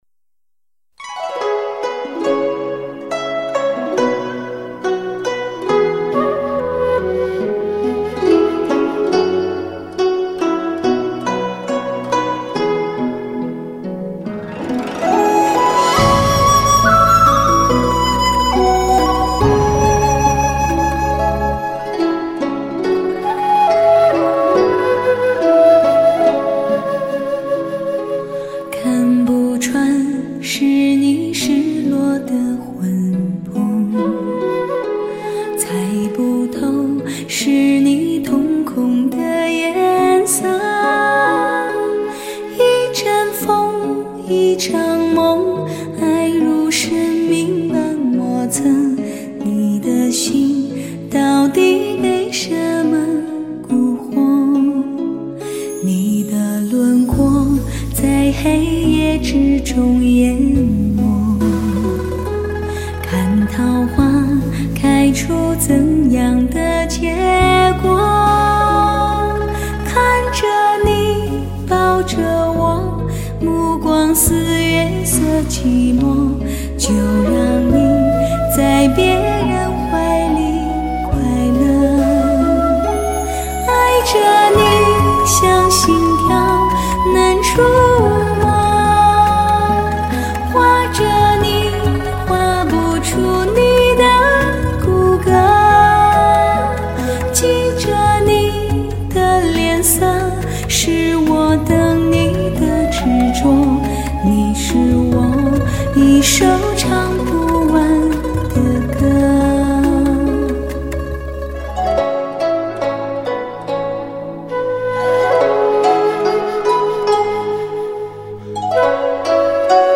重新编曲翻唱版